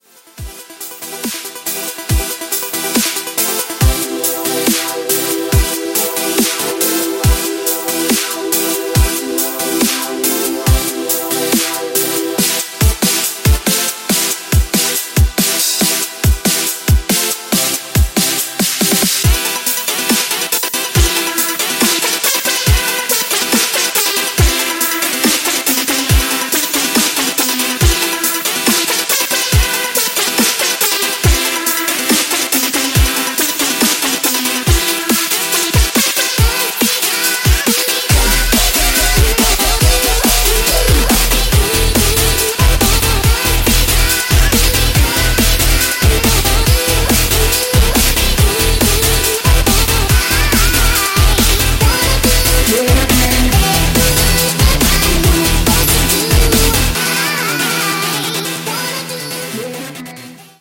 So many sounds! I really like the halfway mark when that bass kicks in.